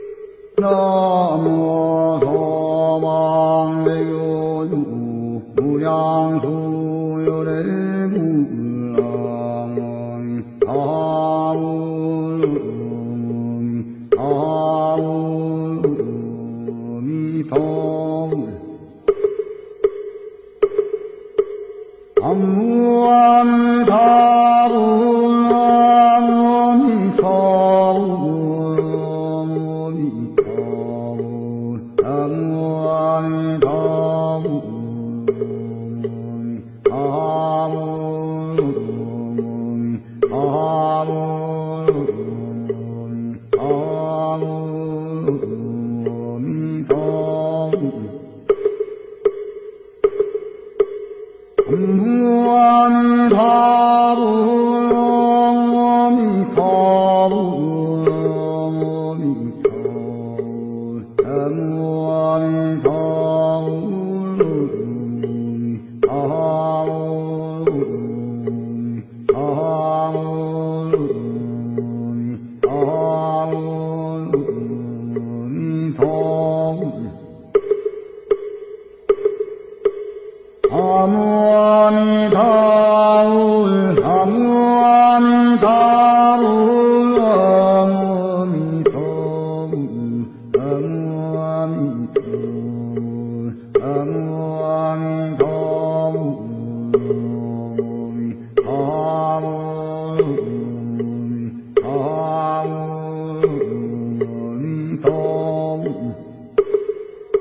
There was a monk inside performing a ceremony.
It was the monk below chanting live. If you’ve never had a chance to hear a Korean Buddhist chanting, here’s a sample: